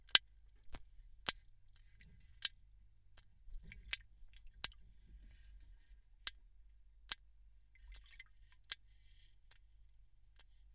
(АУДИО) Слушнете ги првите снимки од ајкули кои прават звуци
Сега, според истражувањето објавено во вторникот во списанието Royal Society Open Science. револуционерна нова студија, откри дека еден вид ајкула може да биде погласен отколку што се мислеше, правејќи звуци споредливи со звукот на пукање на балон.